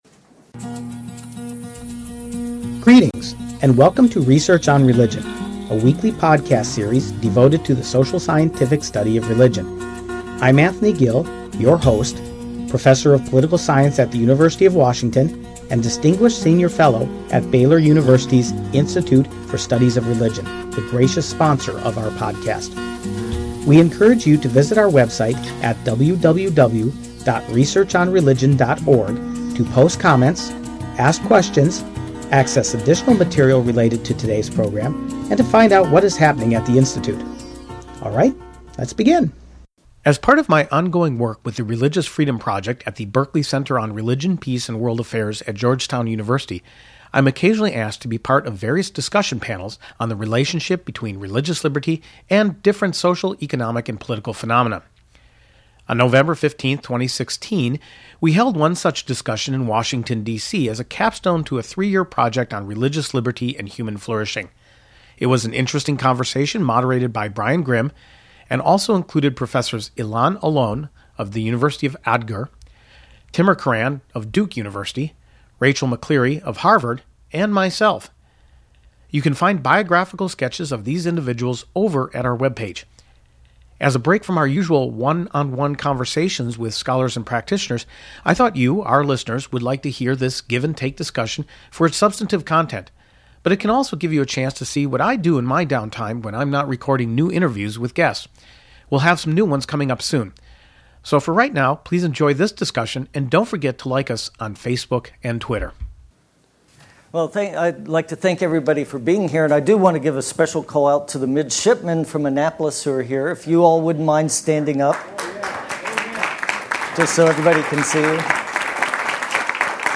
Research On Religion | Is Religious Freedom Good for Growth? A Panel Discussion